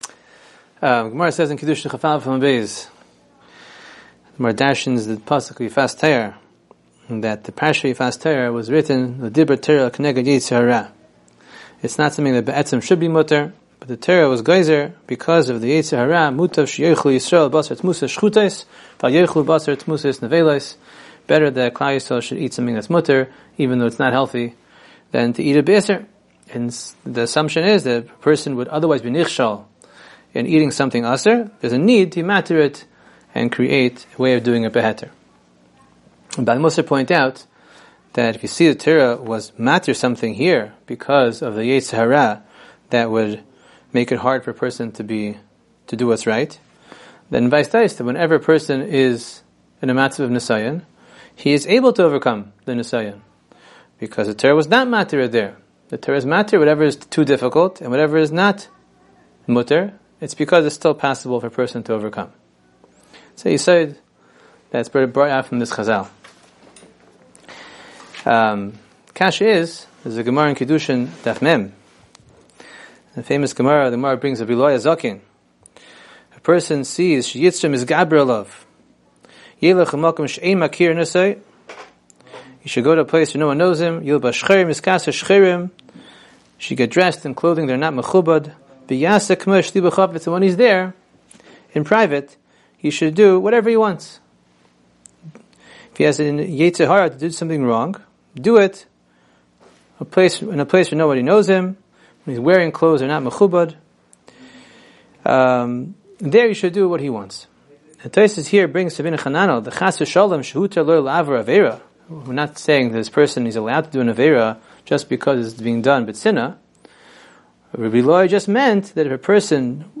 Weekly Alumni Shiur